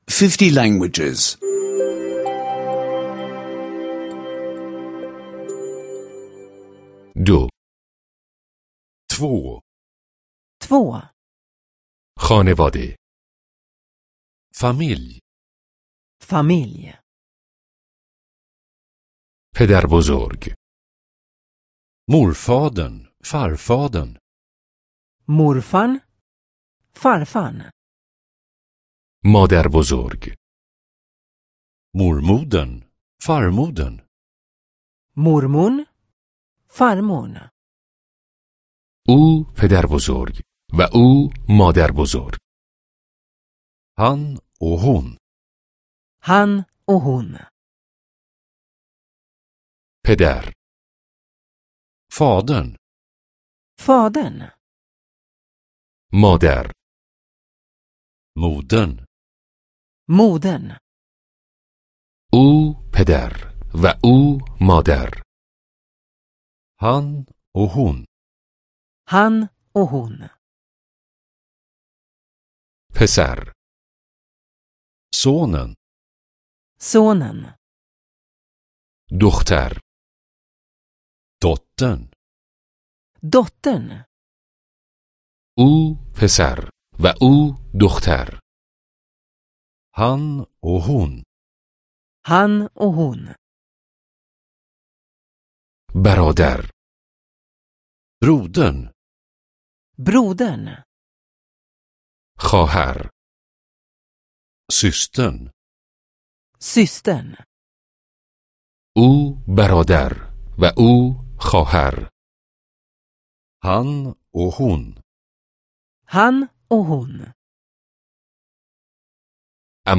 در این صفحه تعدادی از عبارات رایج را در قالب کلیپ‌های صوتی گردآوری کرده‌ایم. این روشی آسان برای تقویت مهارت شنیداری و تلفظ شماست.